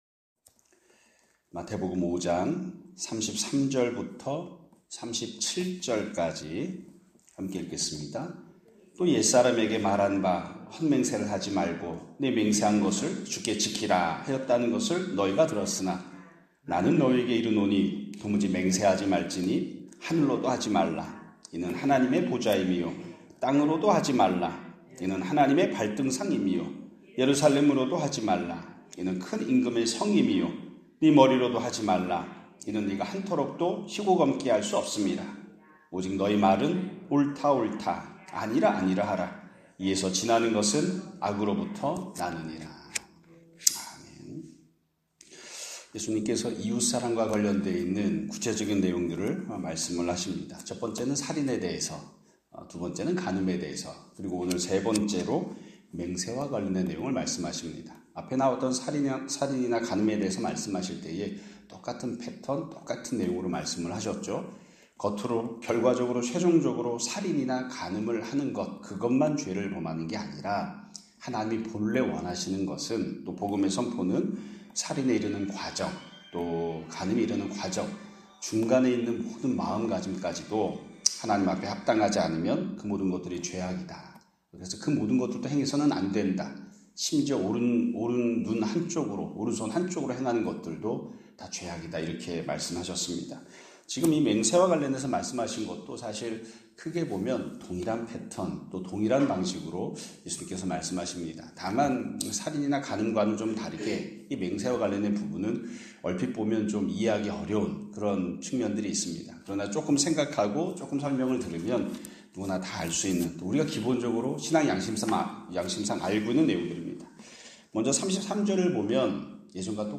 2025년 5월 29일(목요일) <아침예배> 설교입니다.